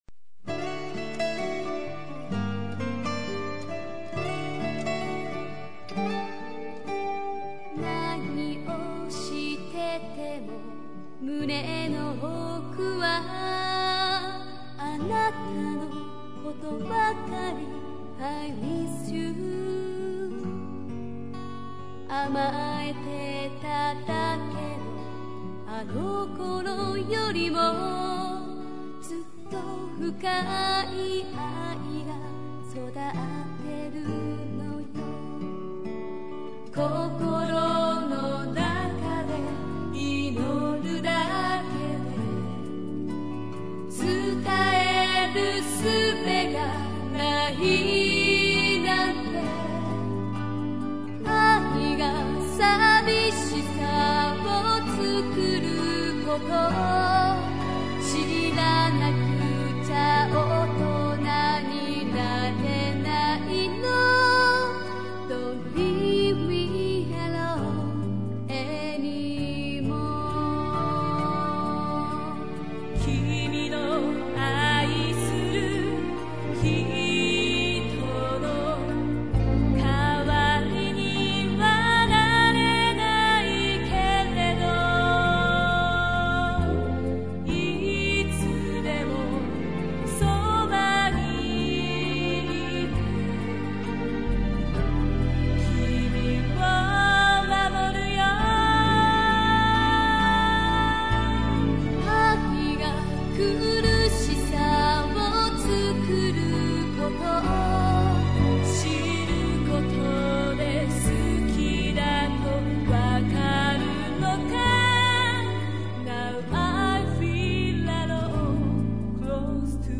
Песня Сейи и Усаги